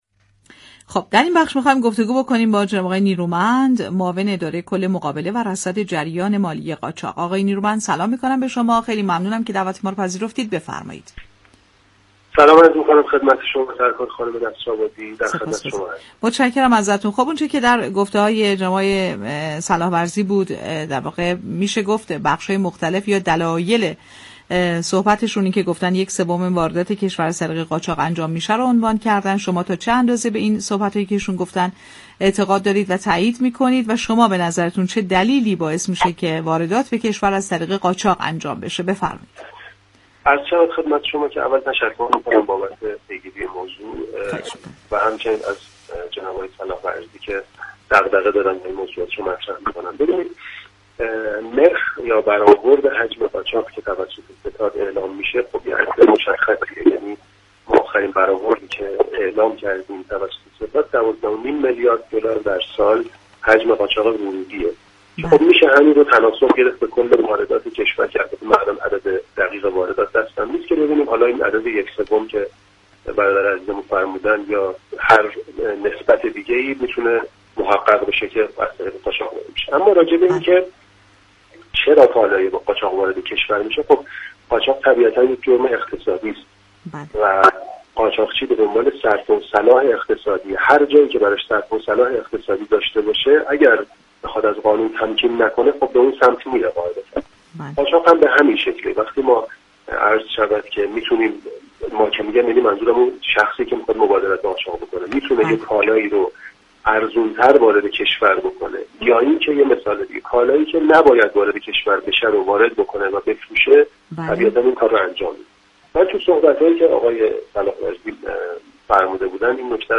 در گفت و گو با برنامه «بازار تهران» رادیو تهران